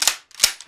ctf_ranged_reload.ogg